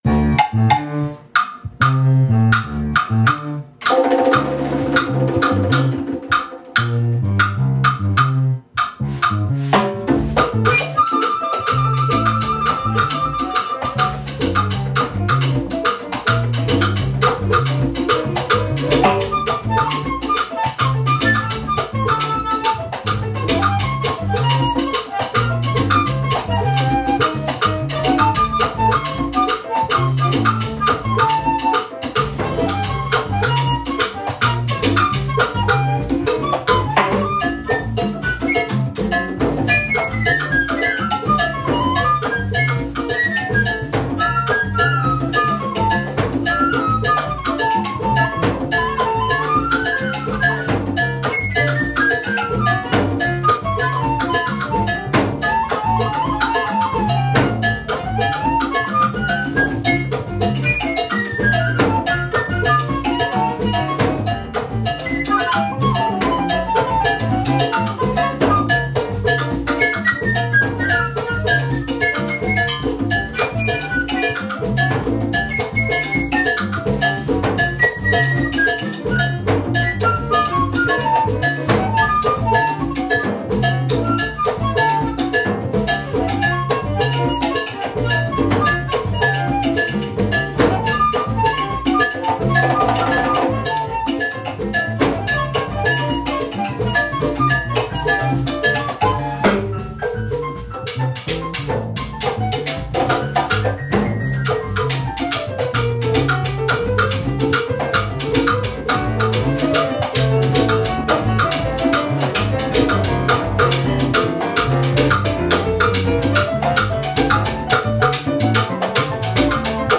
Afro-Cuban jazz
flutes
piano
bass
congas